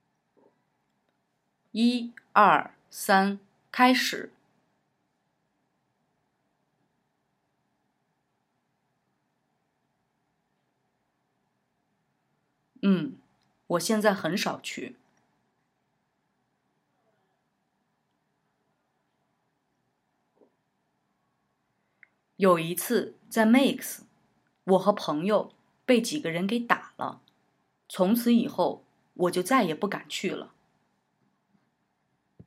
Höre dir Audio 3 an, in dem nur Part B eingesprochen wurde, und übernimm diesmal Part A! Damit du weißt, wann du einsetzen musst, gibt es vorab ein Startsignal.